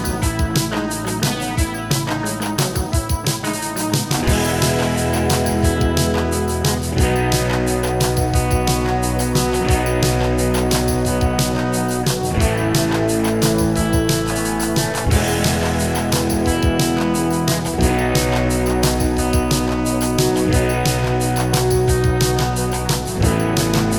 Two Semitones Down Rock 4:37 Buy £1.50